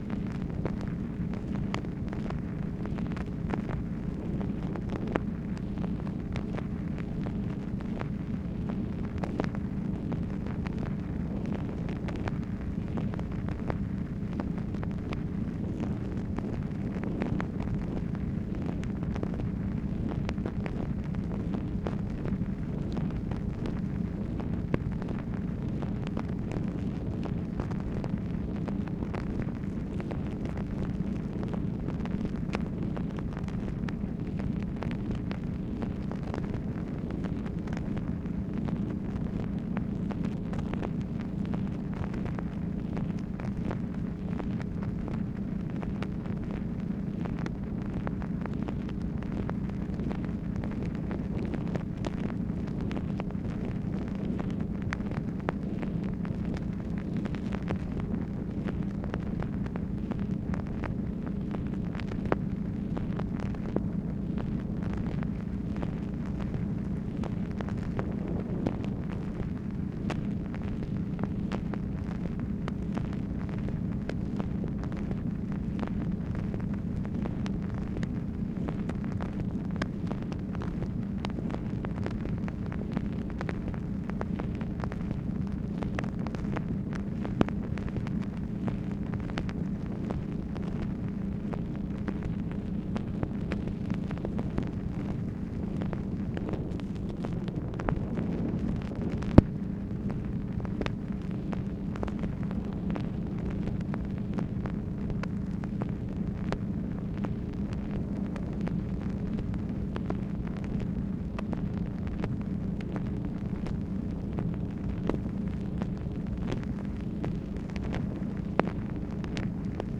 MACHINE NOISE, October 10, 1964